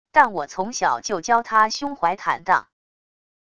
但我从小就教他胸怀坦荡wav音频生成系统WAV Audio Player